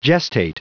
Prononciation du mot gestate en anglais (fichier audio)
Prononciation du mot : gestate